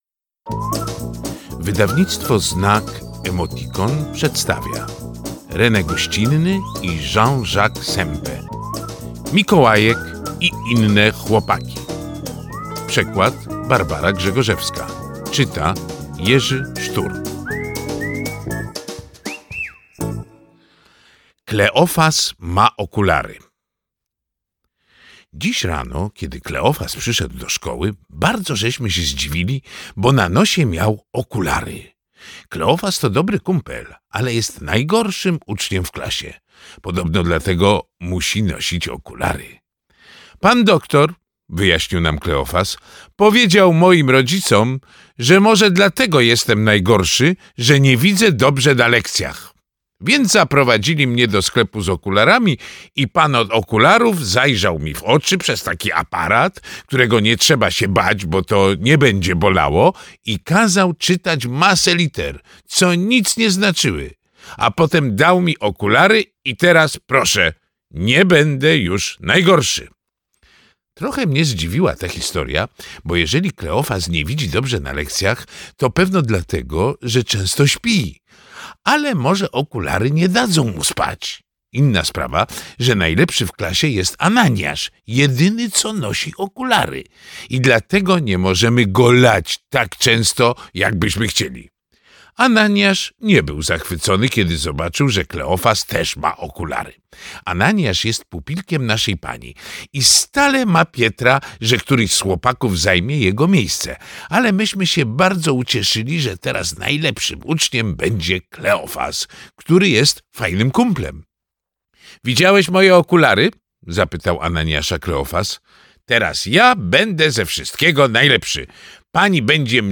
Mikołajek i inne chłopaki - Rene Goscinny, Jean-Jacques Sempe - audiobook